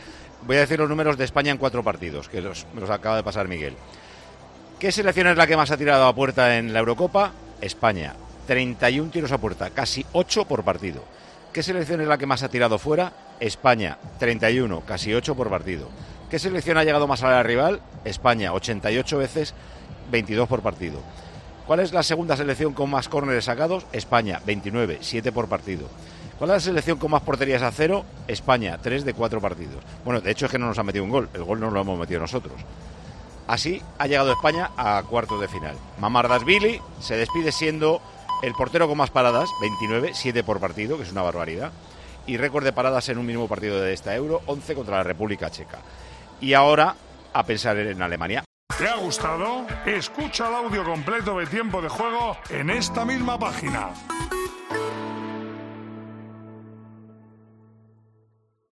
El director de Tiempo de Juego analizó a la conclusión del partido una serie de datos que ensalzan el juego de la selección española en esta Eurocopa.